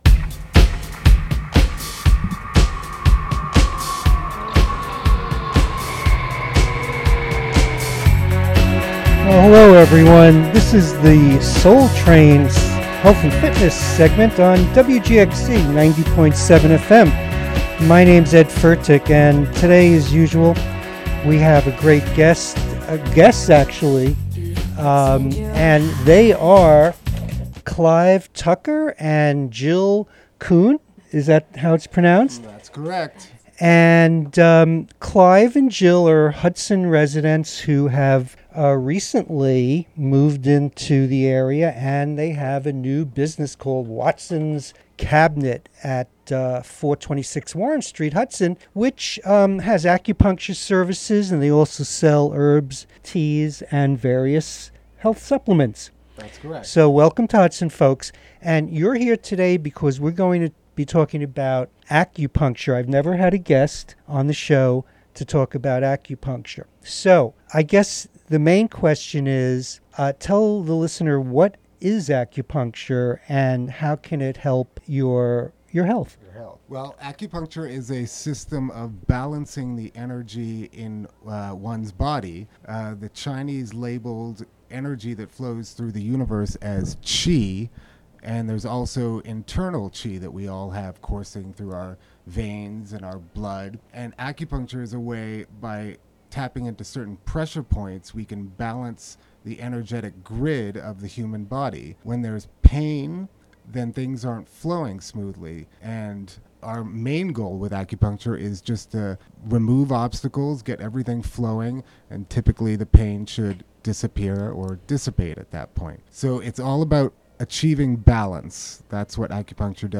Health and fitness segment